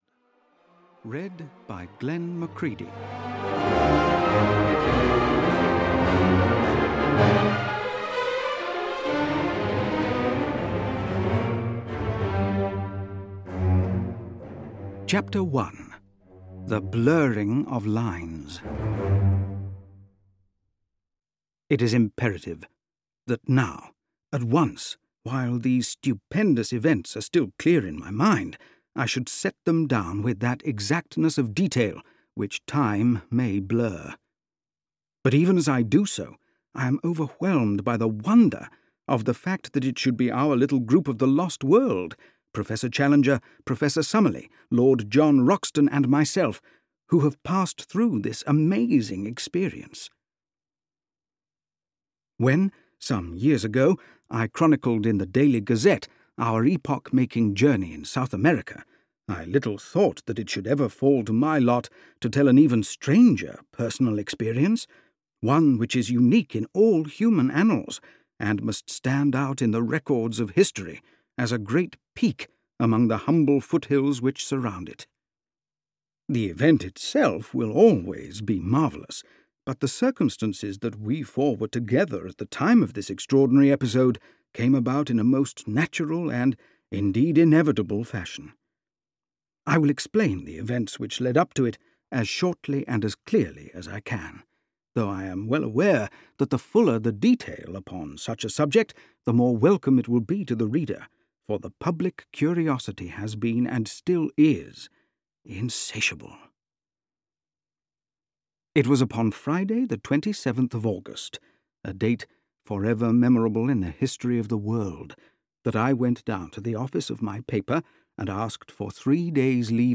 The Poison Belt (EN) audiokniha
Ukázka z knihy